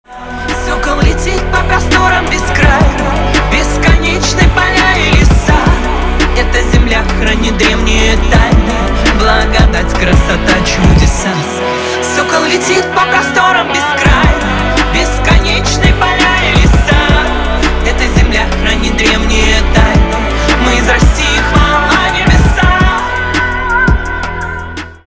русский рэп , гитара
басы